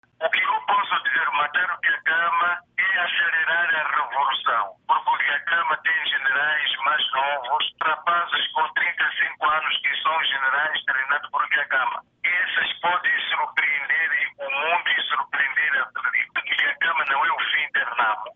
Na entrevista, concedida ao Canal de Moçambique e que a Voz da América publica excertos, Dhlakama diz que se ele morrer a Renamo não acaba.